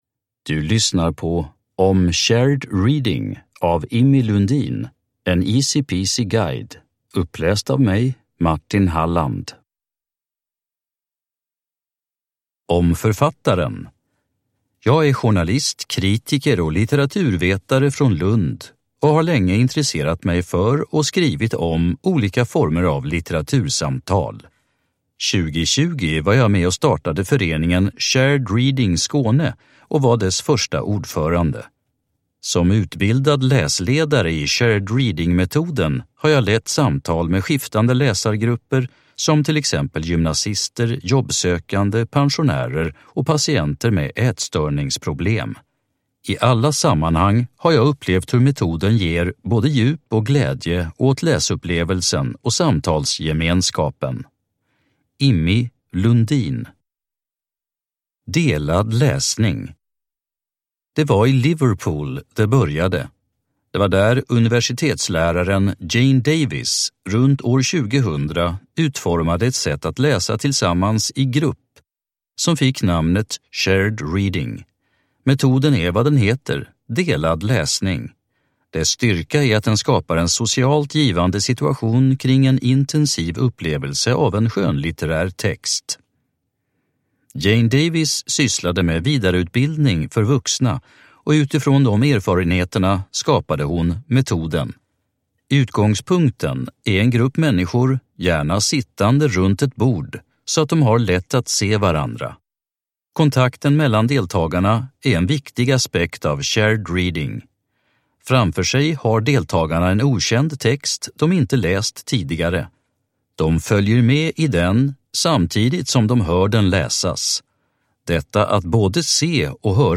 Om Shared Reading (ljudbok